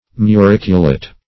Muriculate \Mu*ric"u*late\, a.